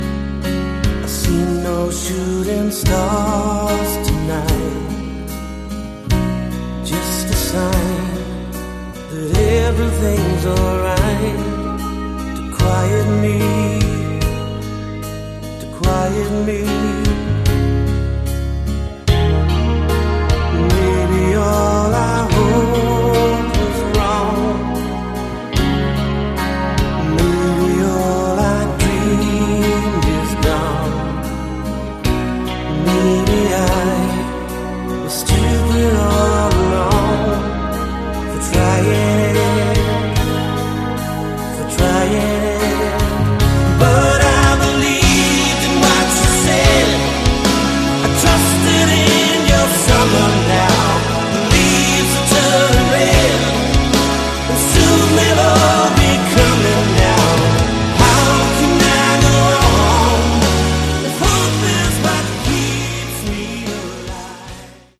Category: Progressive Rock
Vocals
Guitars
Keyboards
Bass
Drums